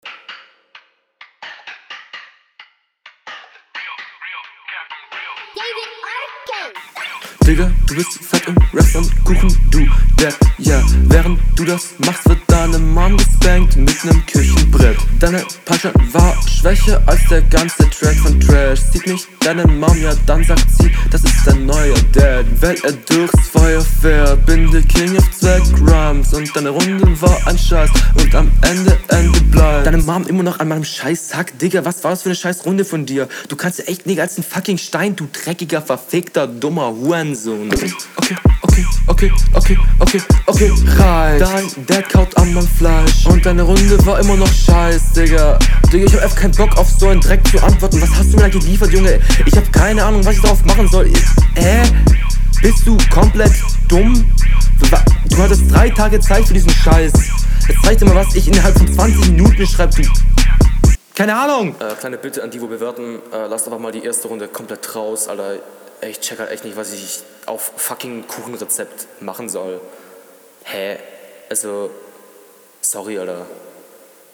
Geiler flow + Battlerunde = Punkt